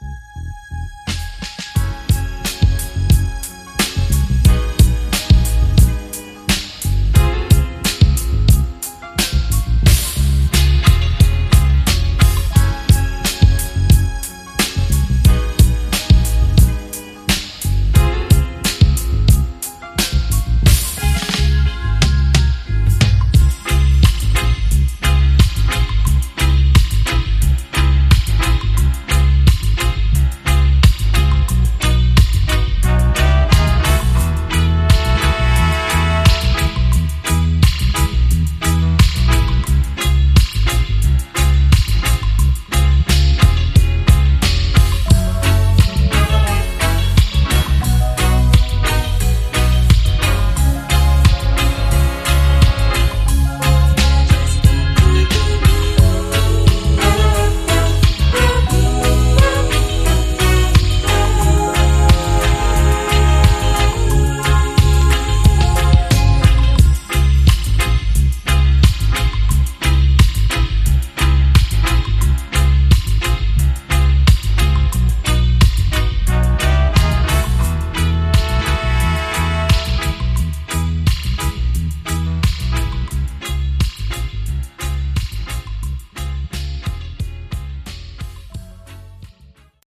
Complete with Instrumental Version on the flip!